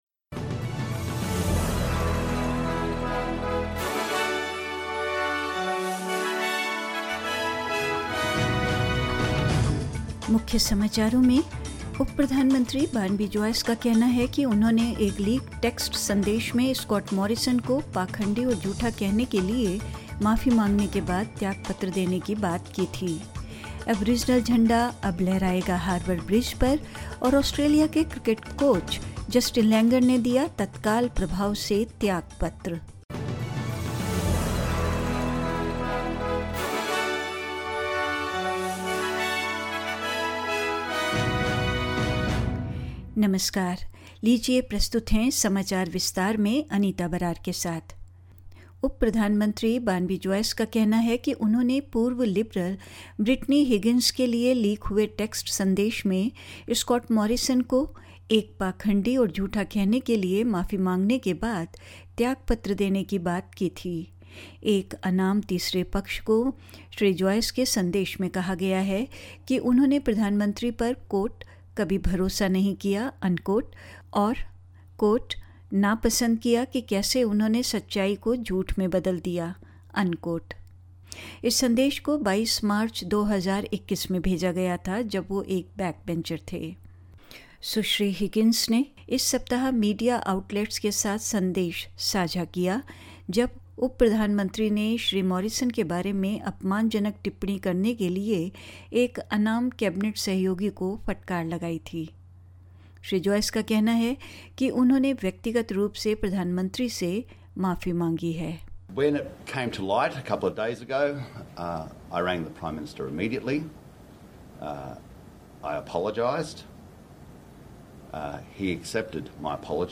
In this SBS Hindi bulletin: Deputy prime minister Barnaby Joyce says he offered to resign after apologising to Scott Morrison for calling him "a hypocrite and a liar" in a leaked text message; The Aboriginal flag will fly permanently on top of the Sydney Harbour Bridge; Australia's men's cricket coach Justin Langer resigns, effective immediately and more news.